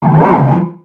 Cri de Judokrak dans Pokémon X et Y.